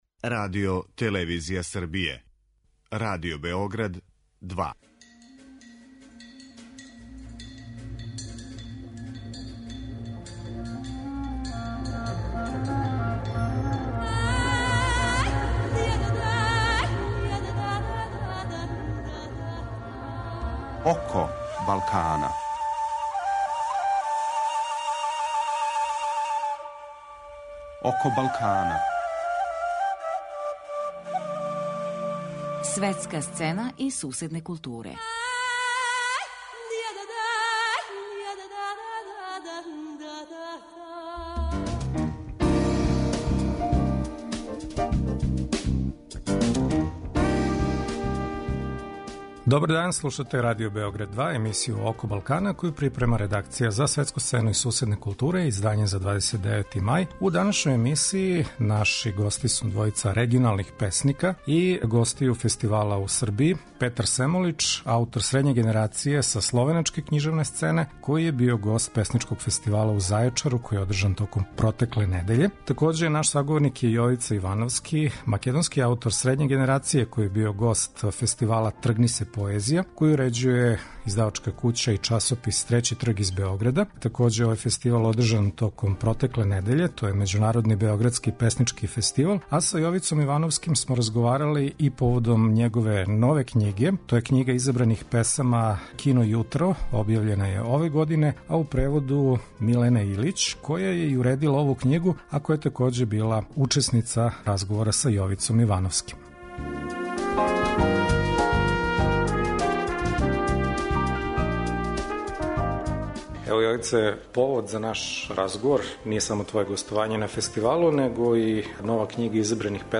У данашњој емисији саговорници су регионални песници и гости домаћих песничких фестивала.